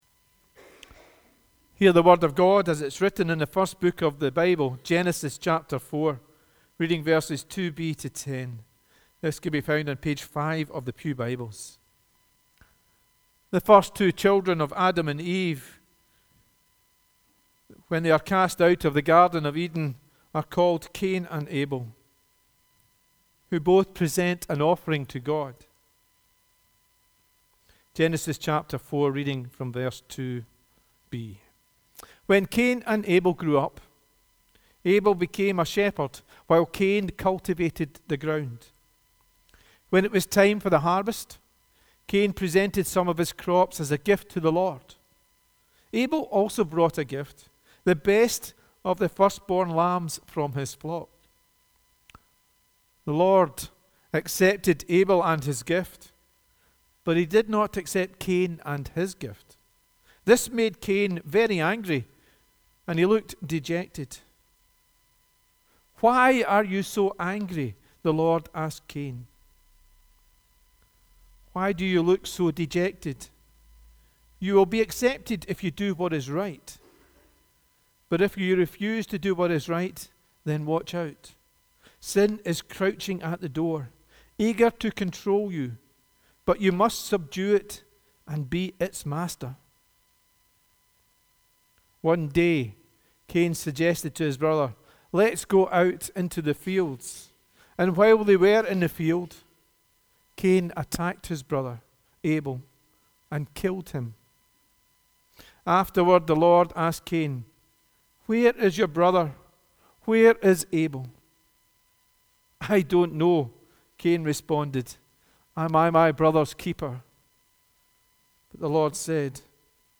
The readings prior to the sermon are Genesis 4: 2b-10 & Obadiah 1: 1-4, 10-18